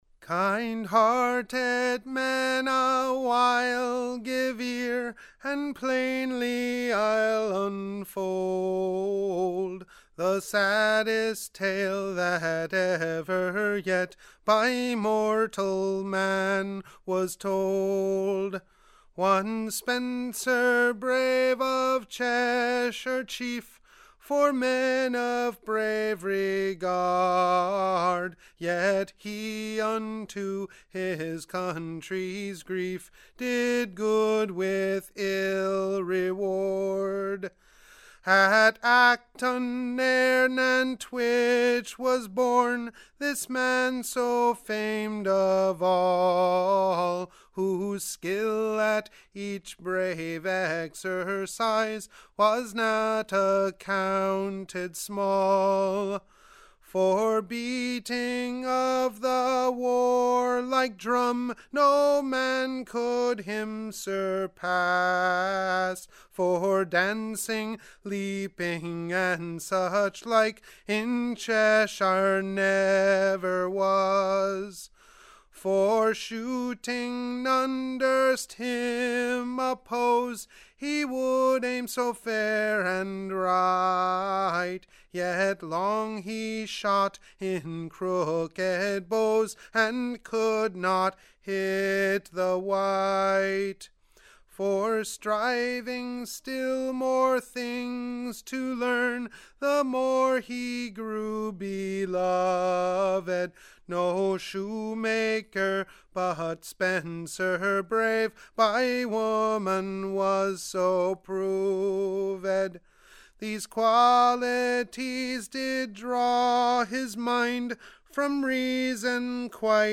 English Execution Ballads